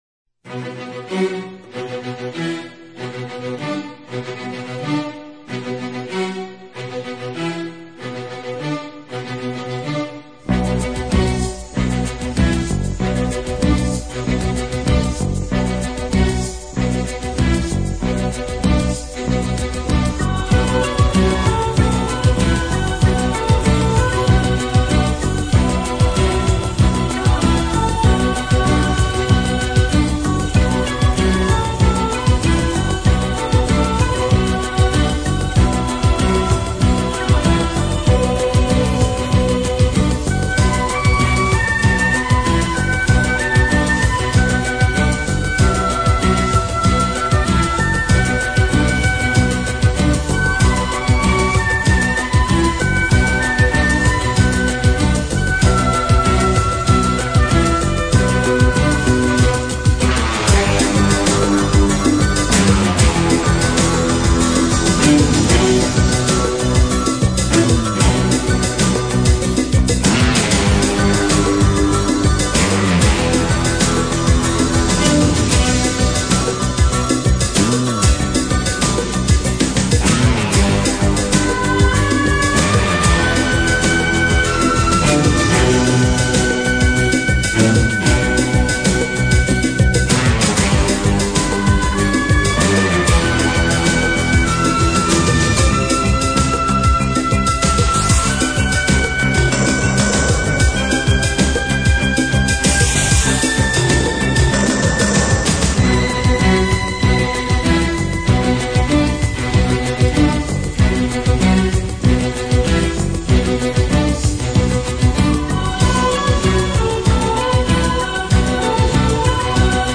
抚慰心灵的听觉效果，仿佛置身漫暖阳光下，独自悠 闲漫游海岸，感受恬静时光。